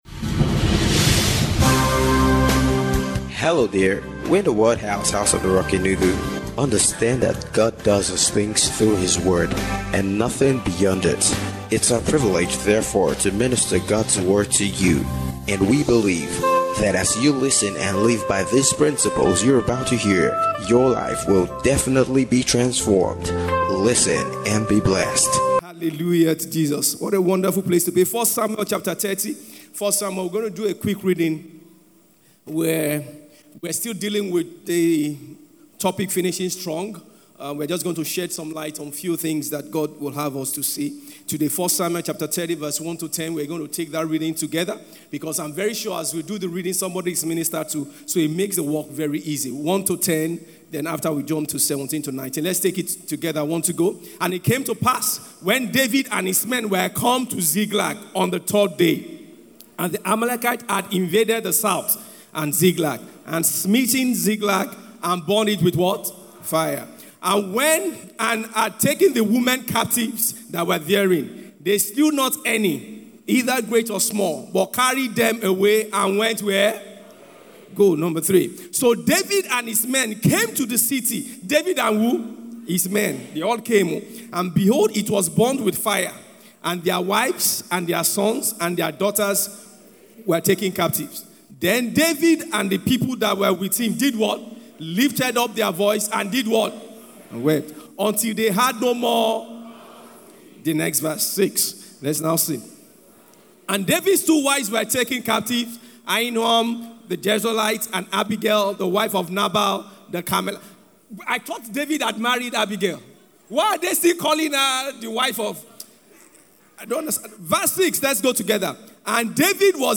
FINISHING STRONG - SUNRISE-SERVICE